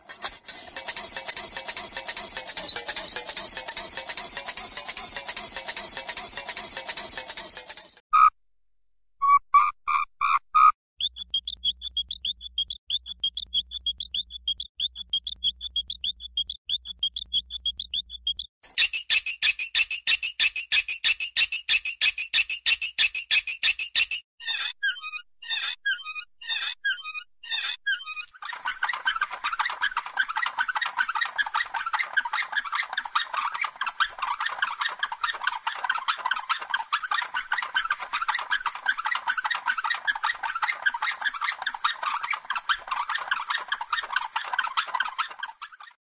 Tổng hợp tiếng Xe Lăn người khuyết tật, bệnh viện….
Thể loại: Tiếng động
Description: Tổng hợp âm thanh của các chiếc xe lăn đang di chuyển trên sàn nhà bệnh viện, tiếng lốp xe lăn lăn trên sàn, tiếng kêu lách tách của bánh xe... tạo nên một bức tranh âm thanh đầy cảm xúc.
tong-hop-tieng-xe-lan-nguoi-khuyet-tat-benh-vien-www_tiengdong_com.mp3